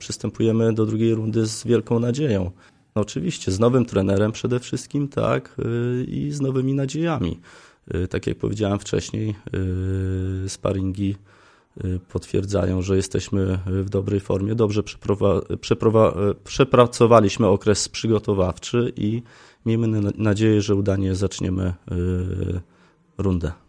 Jesteśmy przygotowani do wiosennej rundy rozgrywek ligowych – zapewniali we wtorek (7.03) na antenie Radia 5